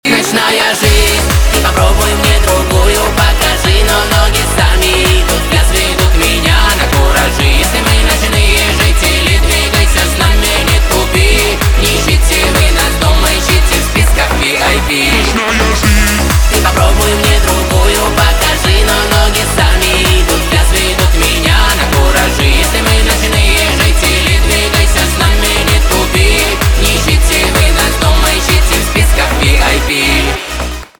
поп
танцевальные